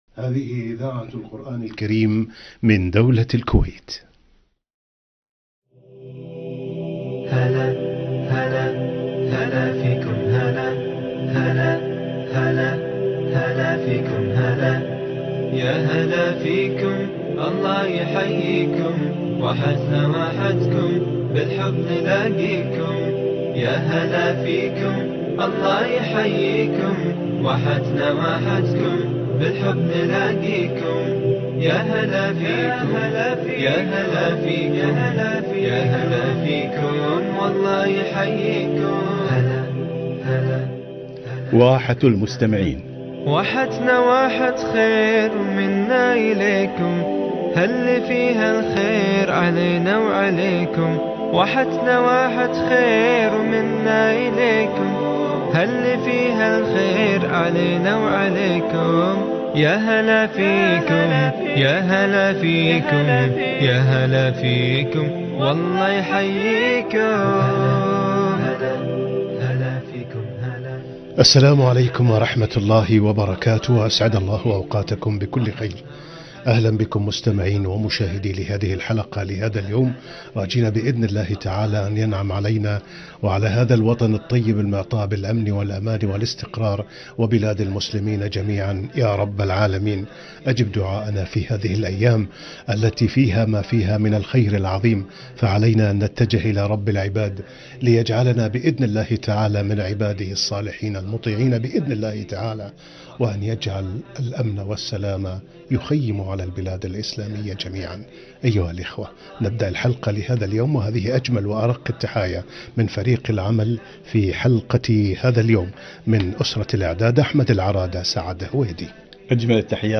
لقاء إذاعي - المطر سنن وأحكام